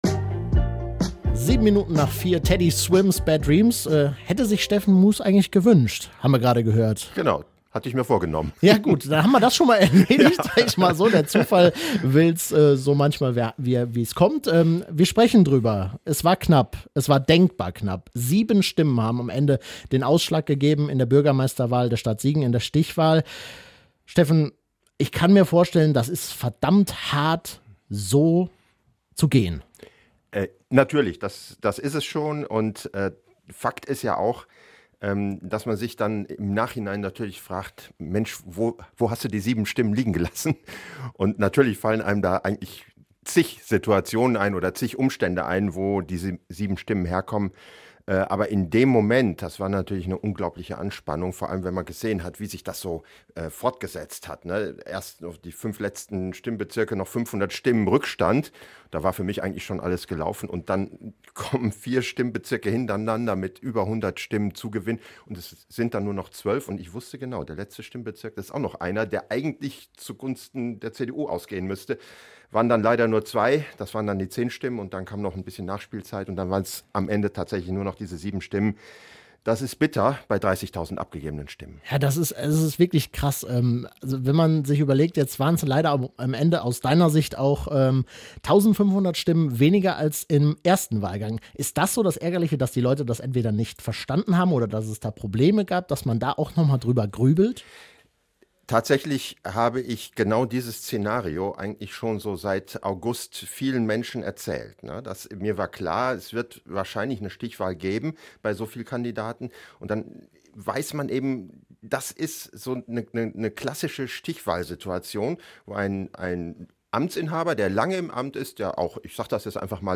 Steffen Mues im ausführlichen Interview
interview-steffen-mues.mp3